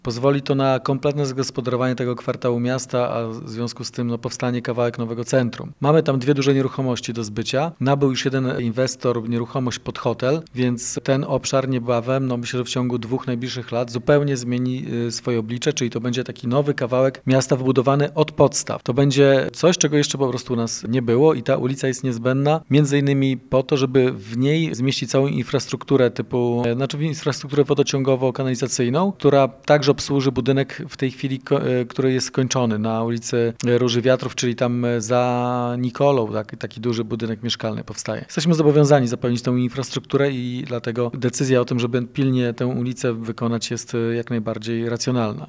Uzupełni ona już istniejące ulice: Żeglarską i Szantową oraz Pasaż Portowy – mówi Wojciech Iwaszkiewicz, burmistrz Giżycka.